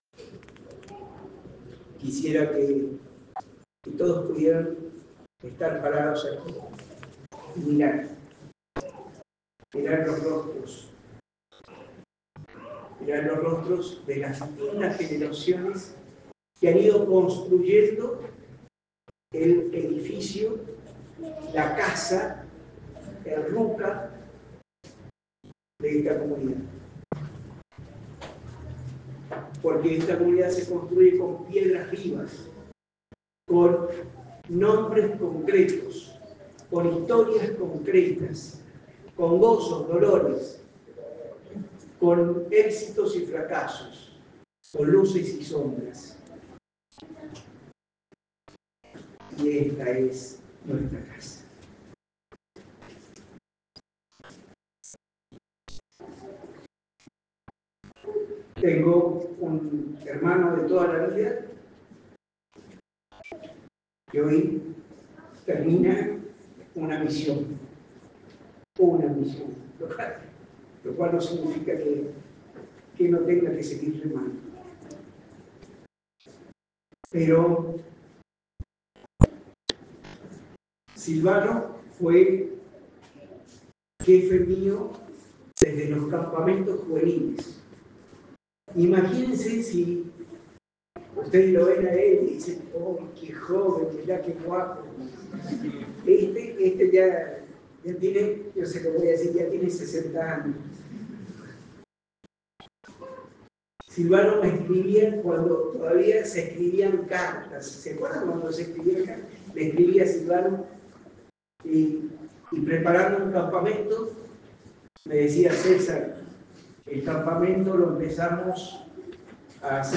durante la homilía